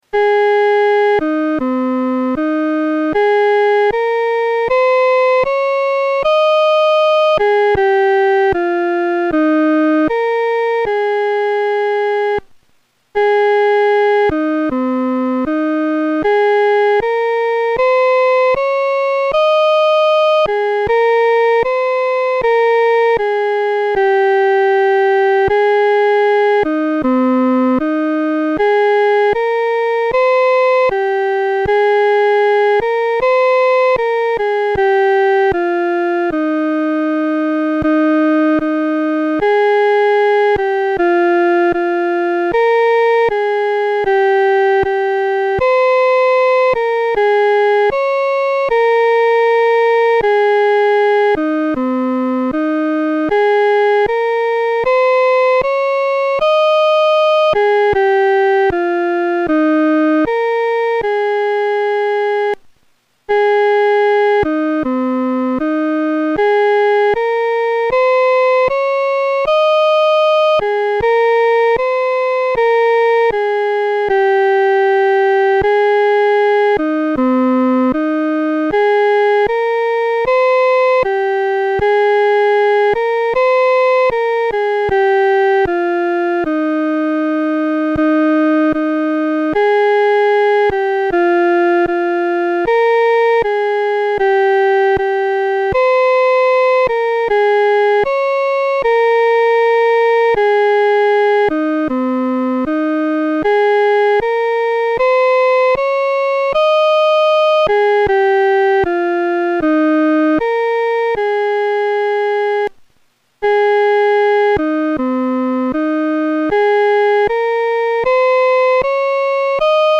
合唱
本首圣诗由网上圣诗班 (青草地）录制
这首圣诗适用中速弹唱。